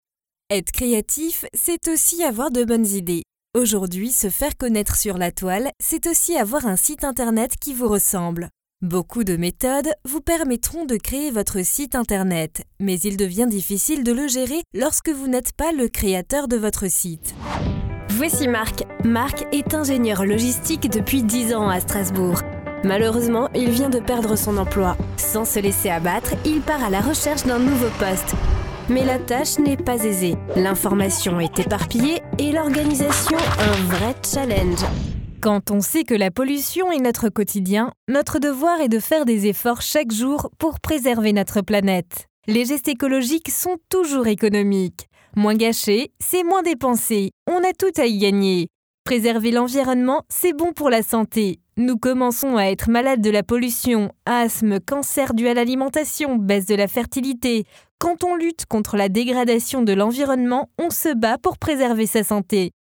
Sprechprobe: Industrie (Muttersprache):
French Voice Over Talent